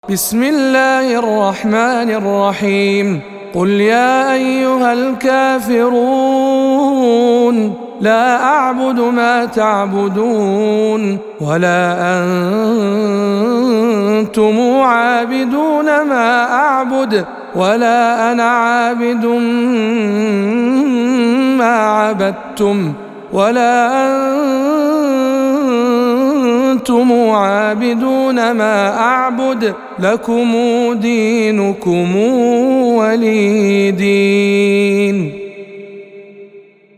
سورة الكافرون - رواية ابن وردان عن أبي جعفر